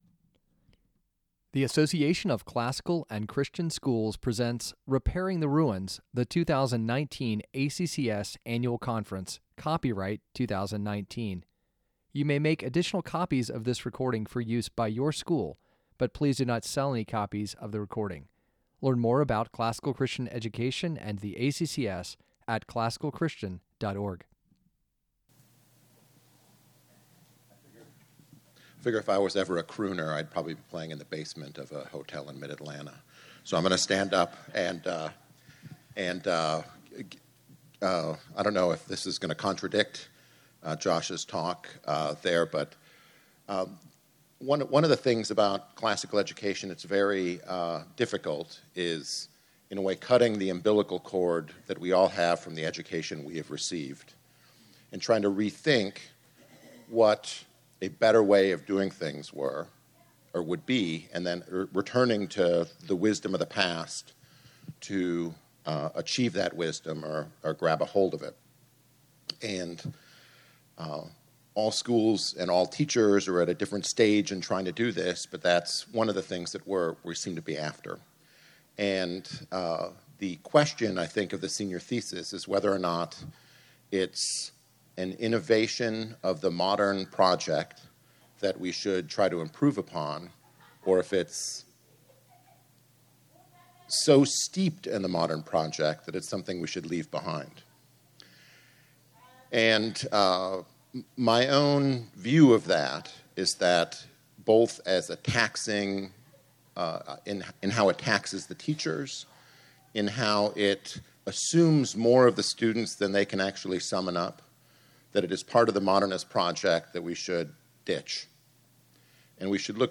Rethink Rhetoric Panel: How to Convert Your Thesis Project – Panel Discussion | ACCS Member Resource Center
2019 Workshop Talk | 49:43 | 7-12, Rhetoric & Composition
PanelDiscussionRethinkRhetoricPanelHOwtoConvertYourThesisPRoject.mp3